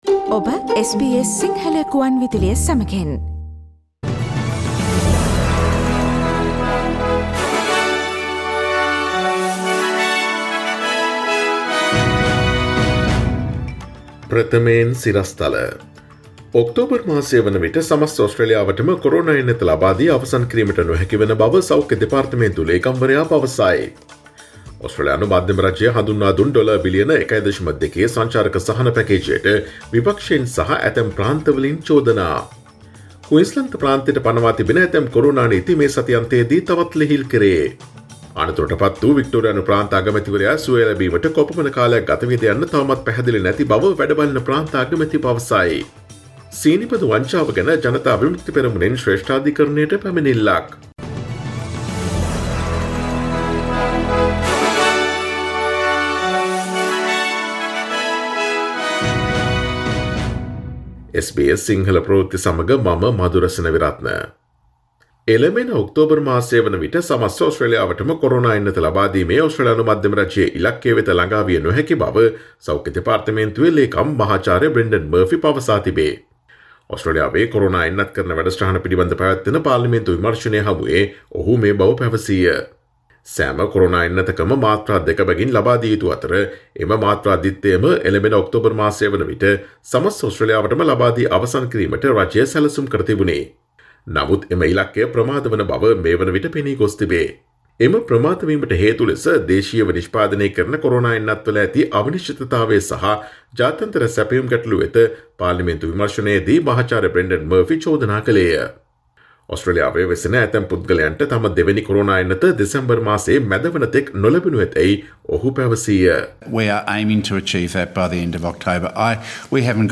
Here are the most prominent Australian, International, and Sports news highlights from SBS Sinhala radio daily news bulletin on Monday 29 March 2021.